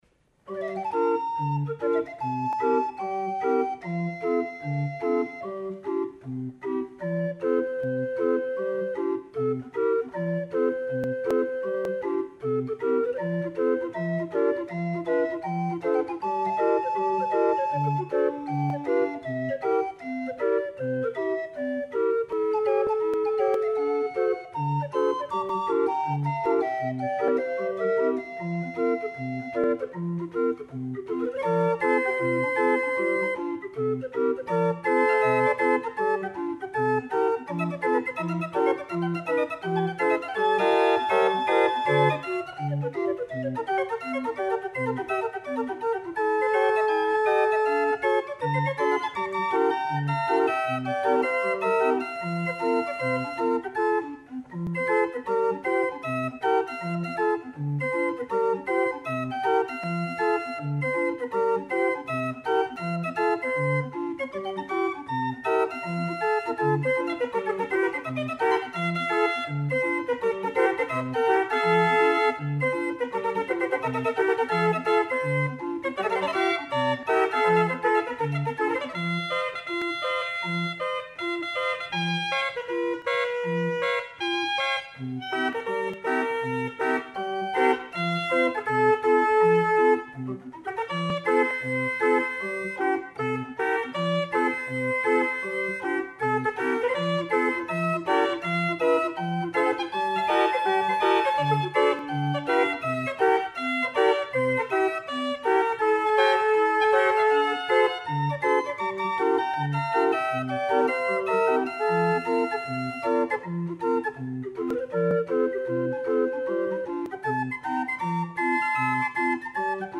The wooden trumpets provide a powerful yet mellow tone.